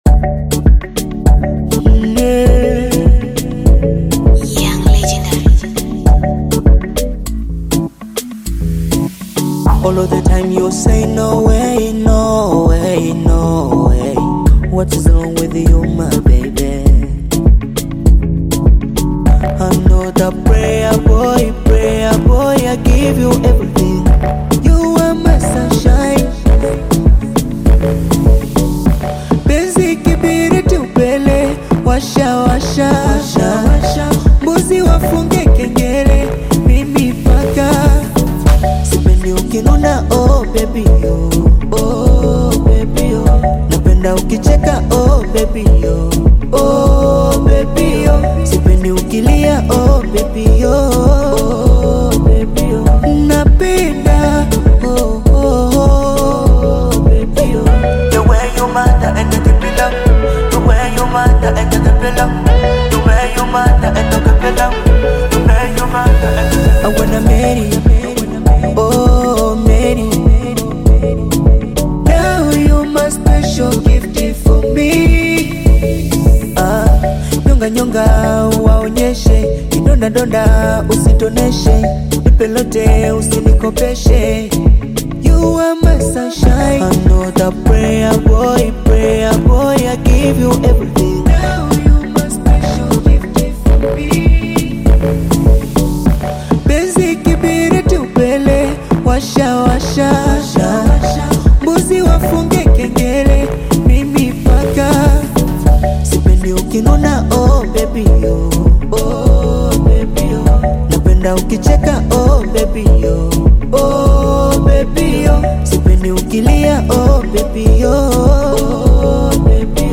Tanzanian Bongo Flava
love song
African Music